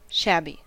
Ääntäminen
Synonyymit ragged mangy downgone Ääntäminen US Tuntematon aksentti: IPA : /ˈʃæb.i/ Haettu sana löytyi näillä lähdekielillä: englanti Määritelmät Adjektiivit Torn or worn ; poor ; mean ; ragged .